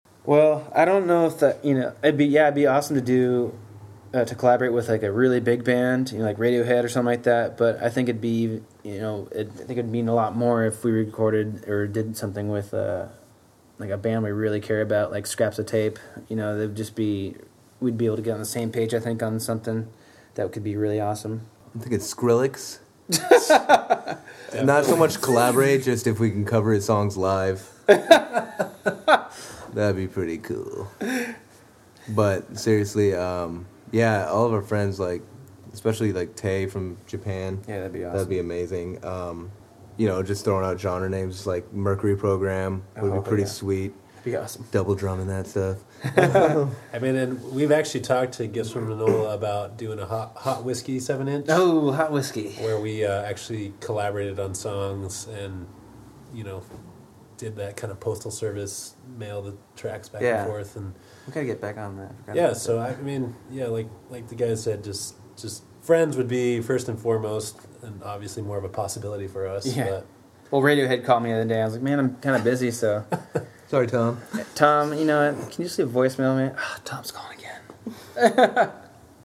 YOU MAY DIE IN THE DESERT INTERVIEW- October 2012